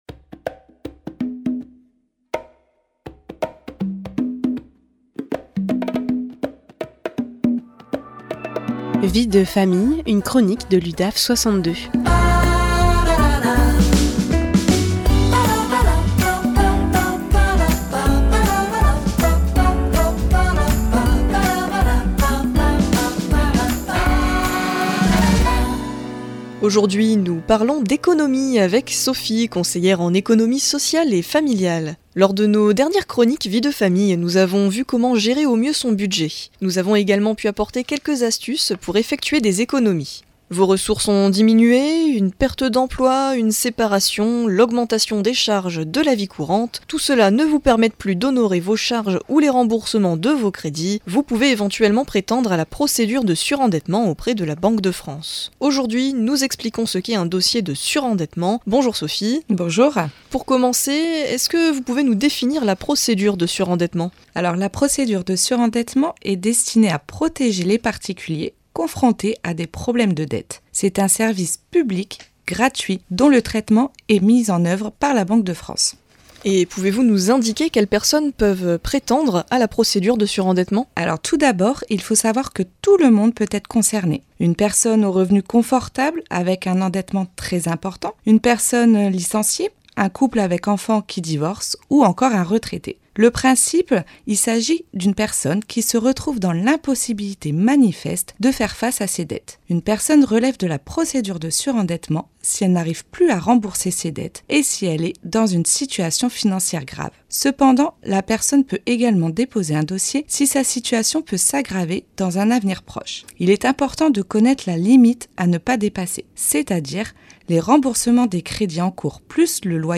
Les professionnels de l’Udaf 62 interviennent au micro de PFM Radio à Arras, en proposant des chroniques sur divers sujets en lien avec leurs services respectifs.
Vie de Famille, une chronique de l’Udaf62 en live sur RADIO PFM 99.9